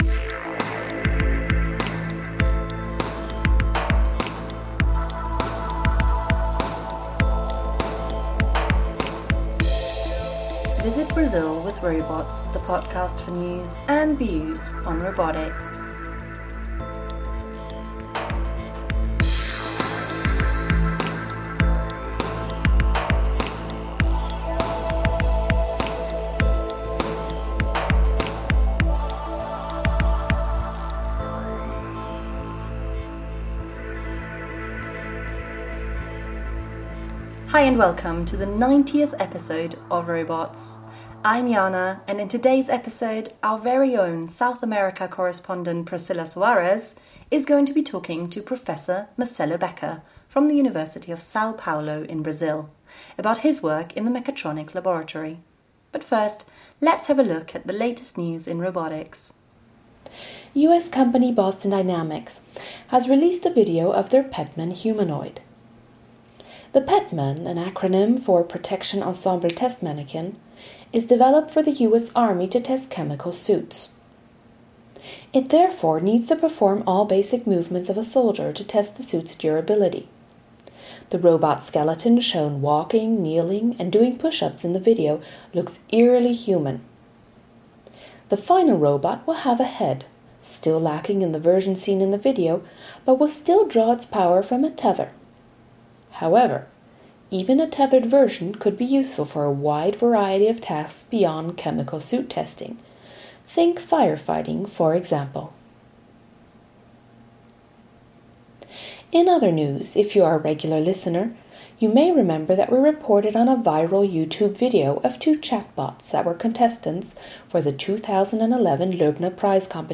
In this interview, we will be talking about one of the main attractions, the robotics competition.